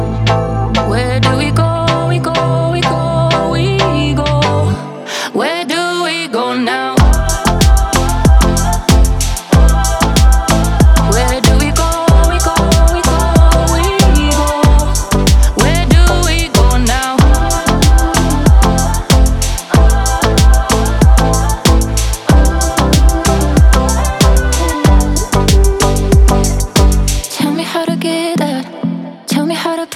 # Электроника